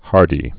(härdē)